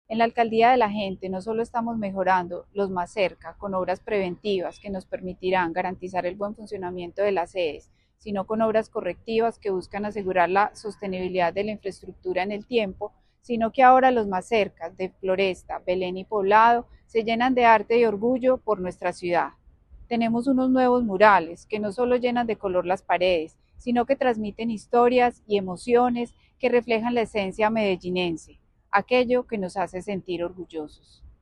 Declaraciones subsecretaria de Servicio a la Ciudadanía, Mónica Henao
Declaraciones-subsecretaria-de-Servicio-a-la-Ciudadania-Monica-Henao.mp3